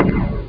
chaingun.mp3